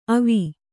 ♪ avi